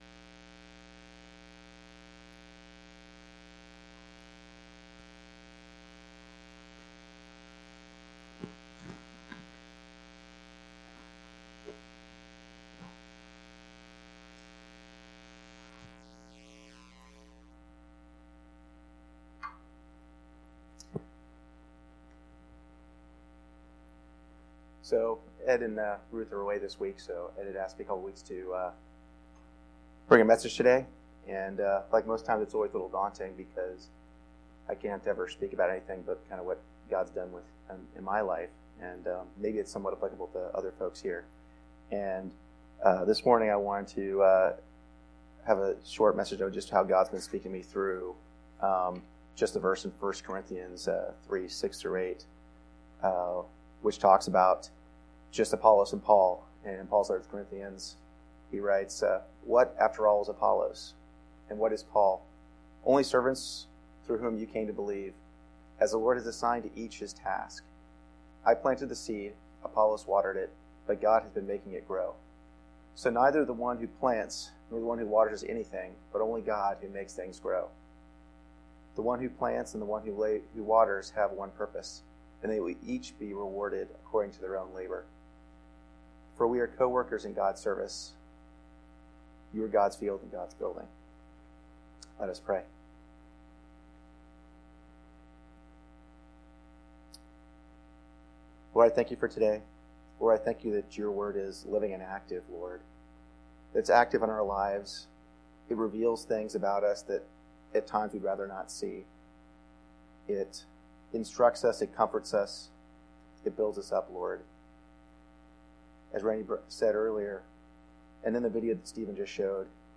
September 15, 2019 Sermons, Speaker
Sermon-9-15-19.mp3